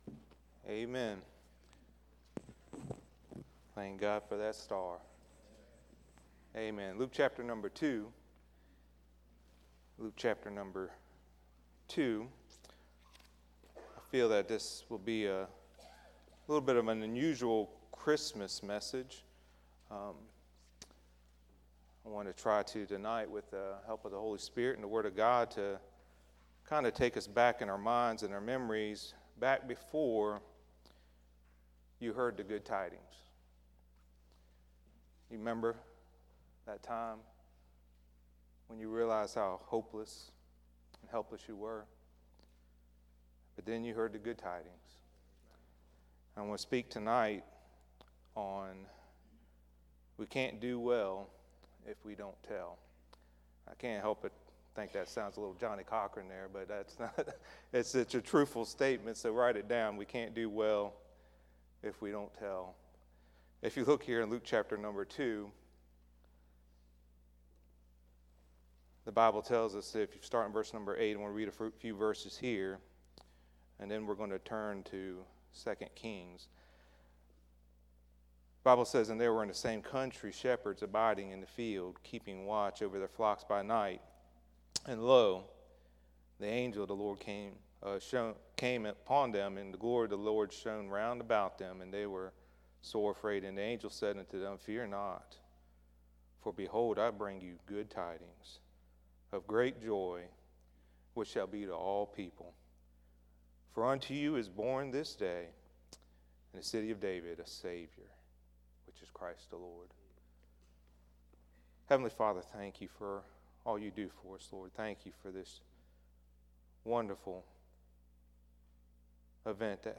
Sermons Archive • Fellowship Baptist Church - Madison, Virginia